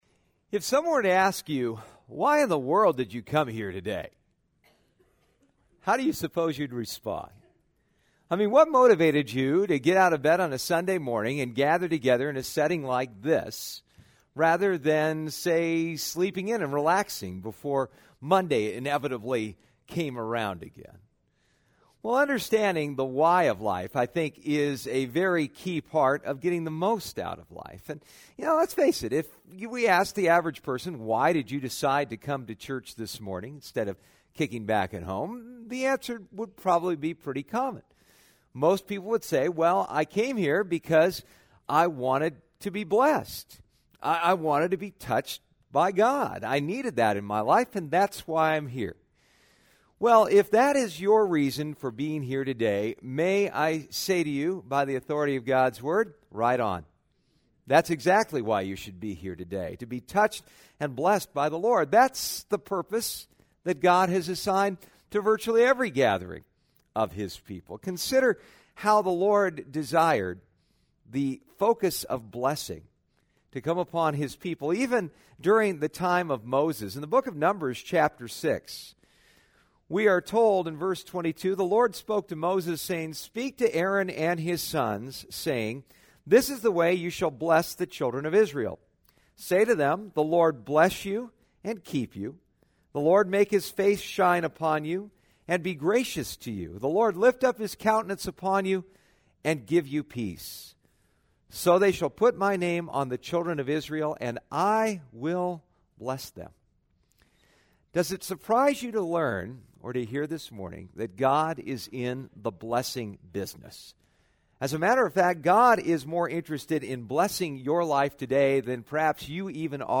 Passage: Psalm 21 Service Type: Sunday Morning « When God Touches Your Life